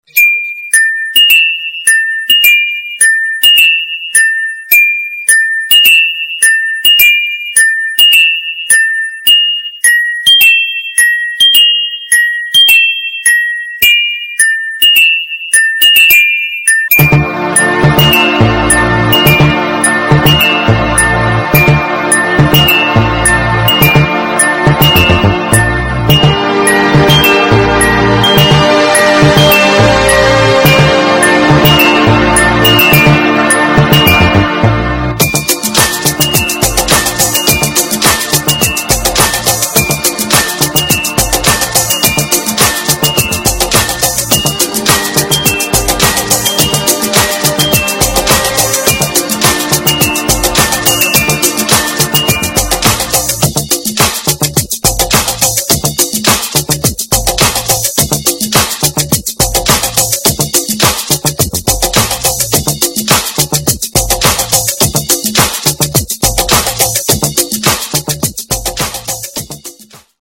• Качество: 320, Stereo
громкие
без слов
красивая мелодия
инструментальные
звонкие
Стандартная мелодия на телефоне Верту.